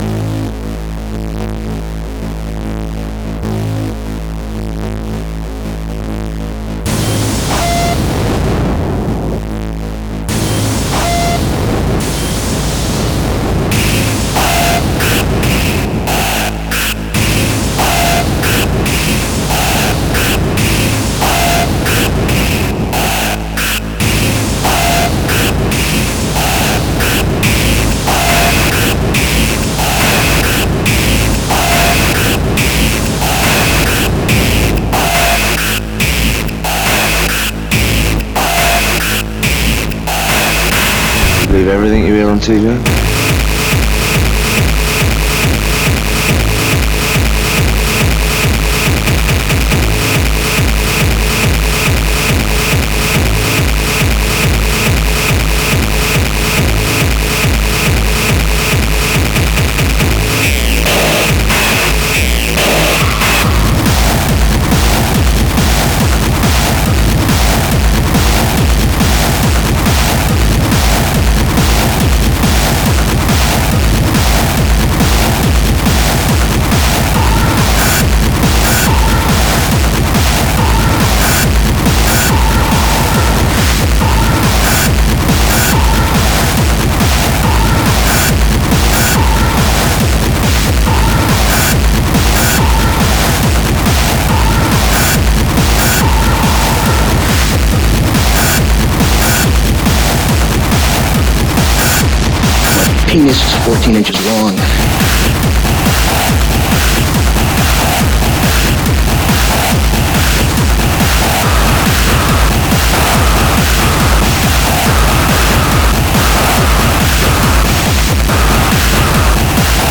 EBM, Industrial, Rhythmic Noise